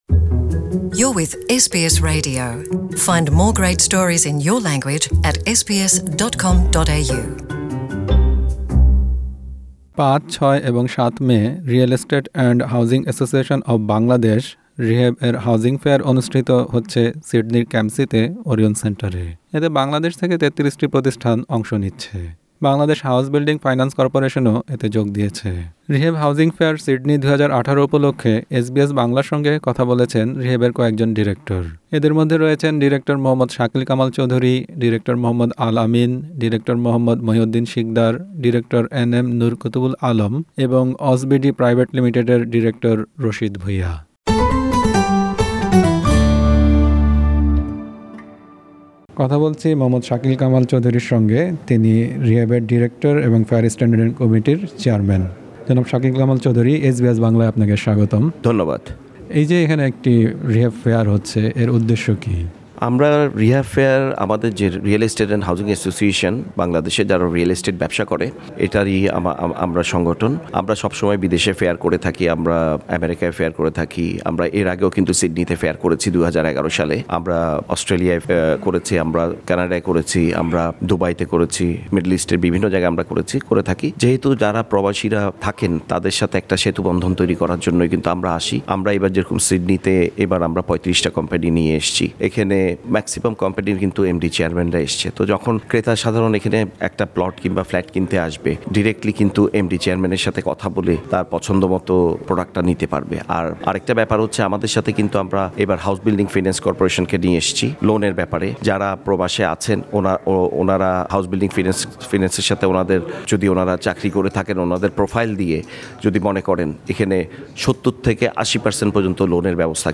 বাংলায় তাদের সাক্ষাৎকার শুনতে উপরের মিডিয়া প্লেয়ারটিতে ক্লিক করুন।